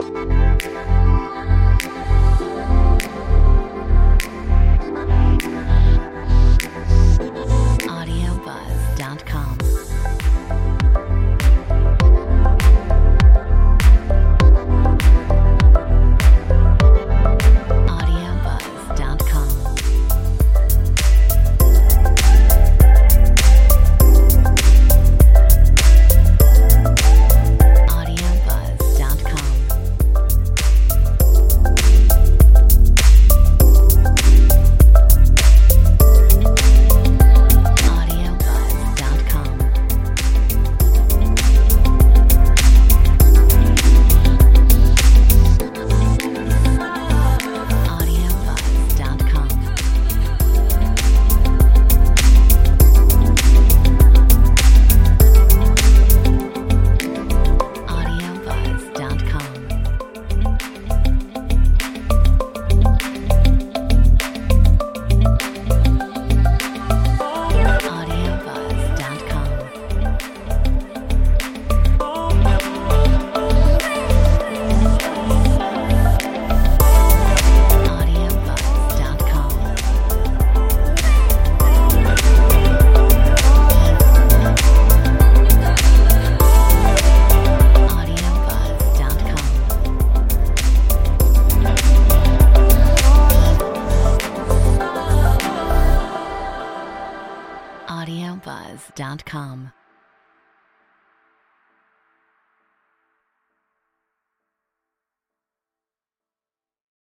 Metronome 100